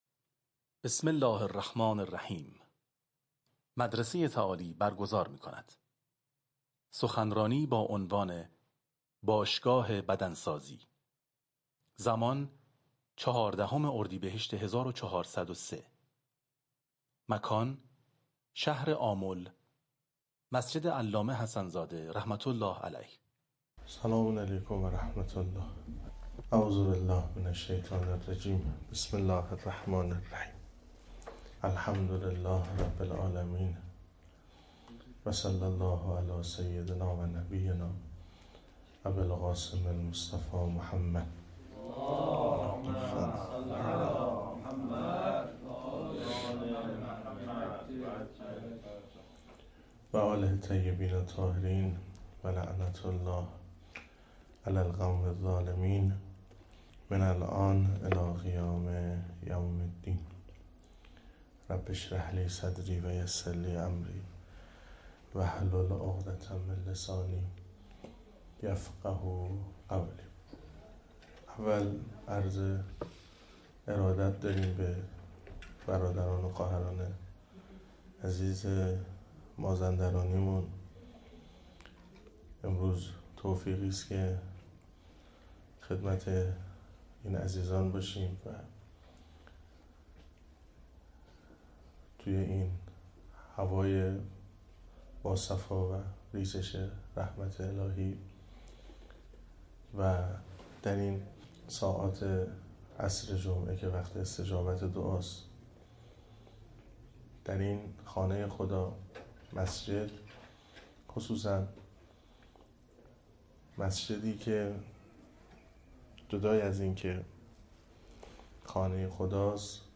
جلسه افتتاحیه شذرات مدرسه تعالی